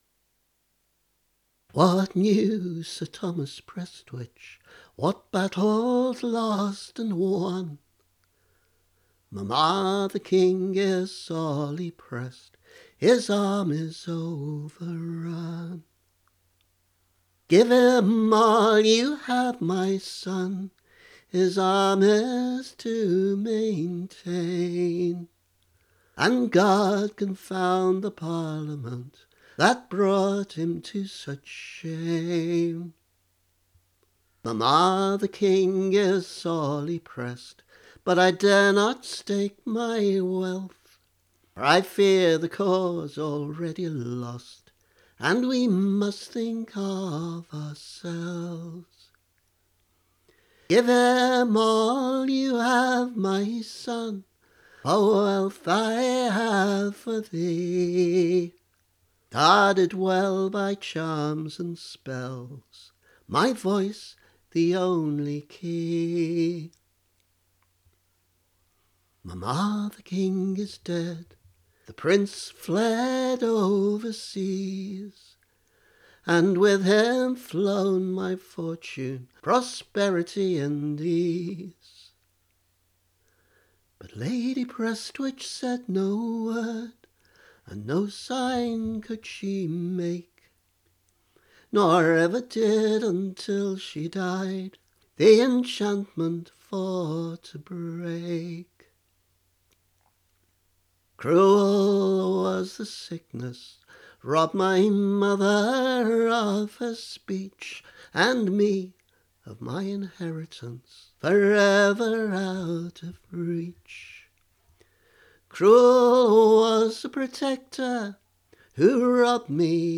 The tune is based on a traditional tune associated with the song The Wars In Germany, though it occurs to me that the tune to Derwentwater’s Farewell would also fit.